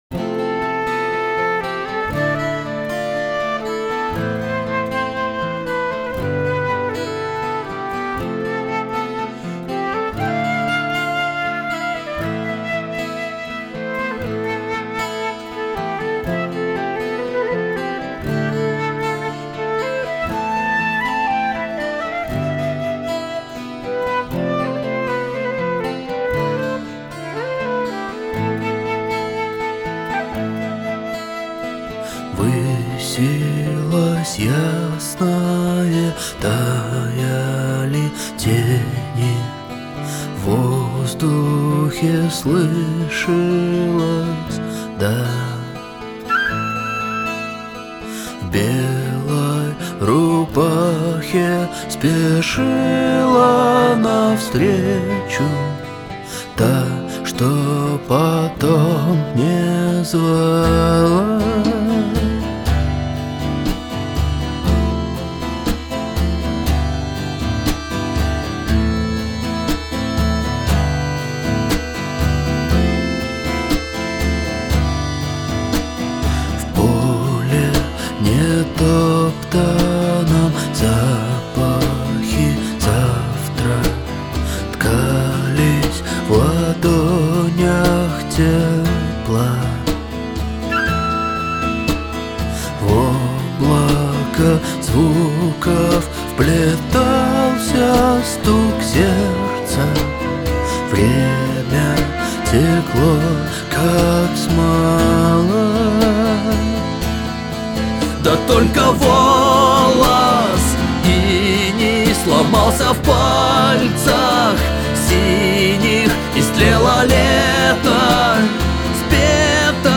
2 акустических блока